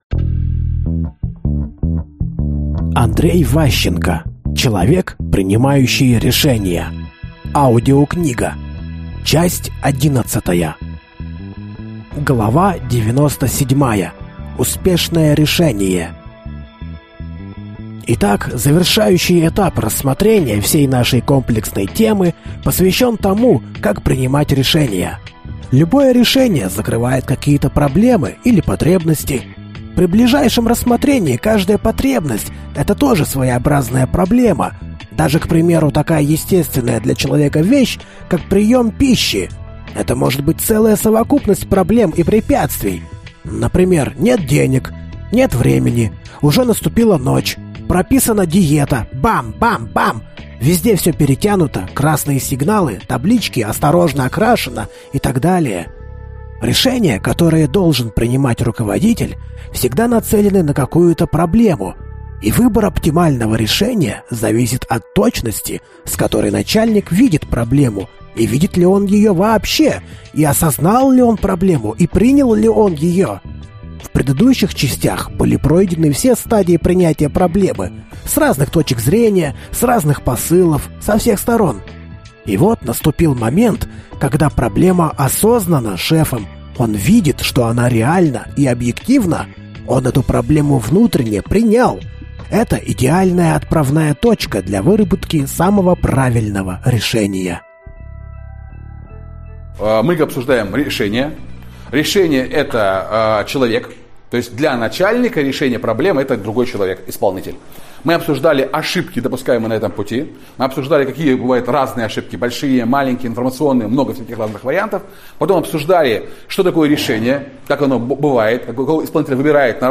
Аудиокнига Человек, принимающий решения. Часть 11 | Библиотека аудиокниг